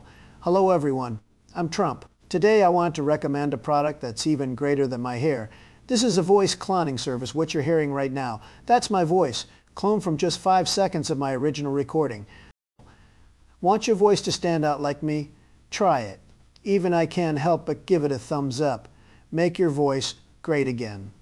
Clone any voice in 3 seconds – hyper-realistic and free
🚀 Don’t believe it? Listen to this Trump clone we made from just 3 seconds of audio: